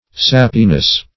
Sappiness \Sap"pi*ness\, n. The quality of being sappy; juiciness.